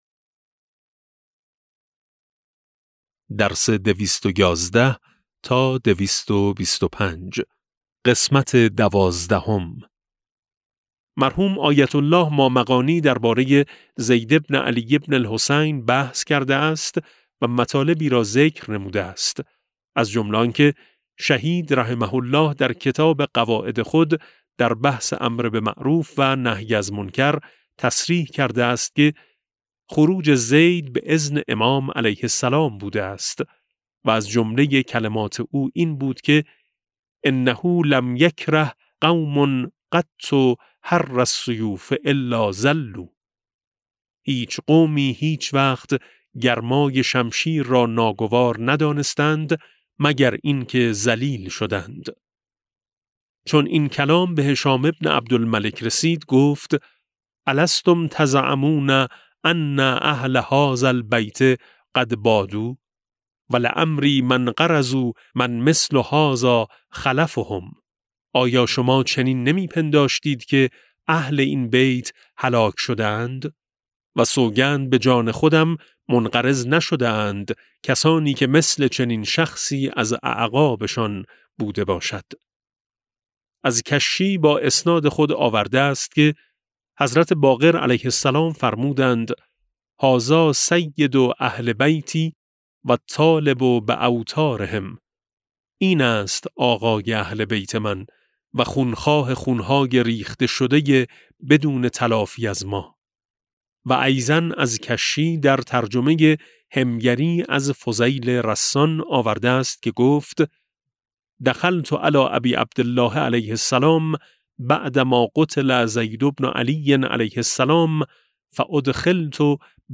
کتاب صوتی امام شناسی ج15 - جلسه12